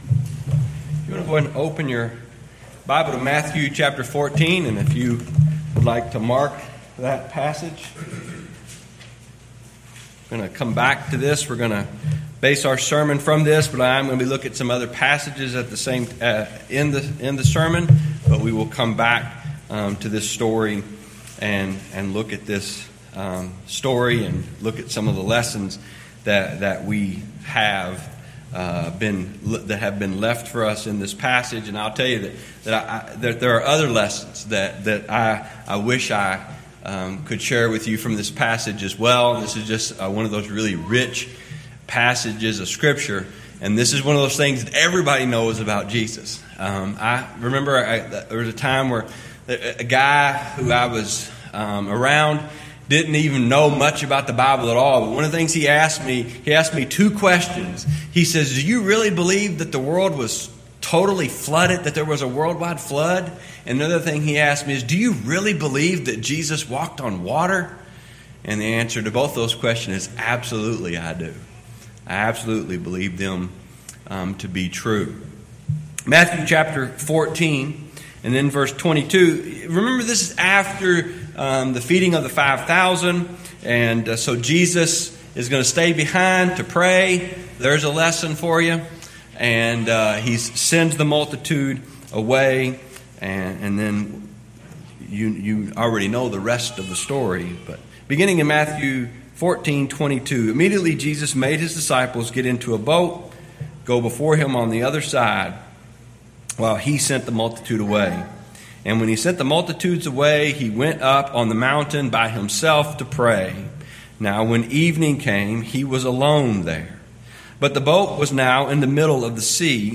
Series: Gettysburg 2017 Gospel Meeting